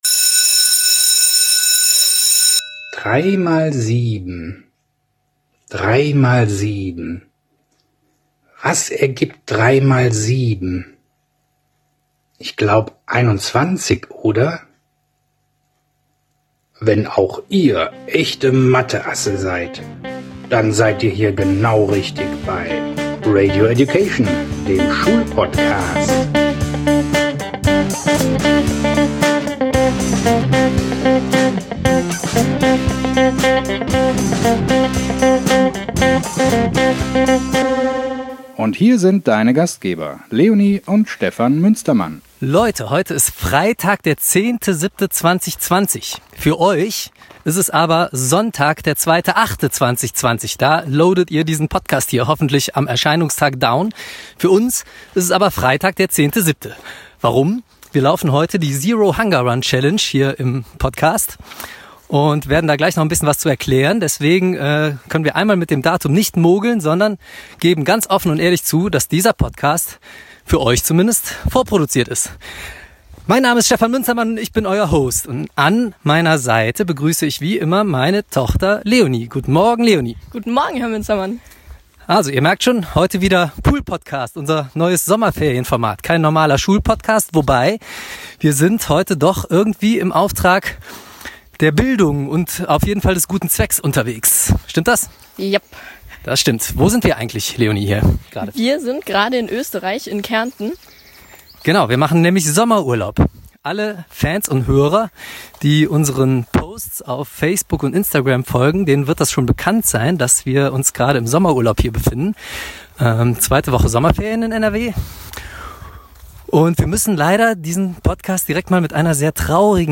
DER WELTWEIT ERSTE PODCAST, DER BEIM LAUFEN AUFGENOMMEN WURDE!!! Durchlebt mit uns in unserem Ferienformat "der Poolpodcast" noch einmal, wie wir für den guten Zweck laufen!!